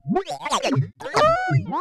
AFX_DROIDTALK_3_DFMG.WAV
Droid Talk 3